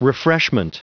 Prononciation du mot refreshment en anglais (fichier audio)
Prononciation du mot : refreshment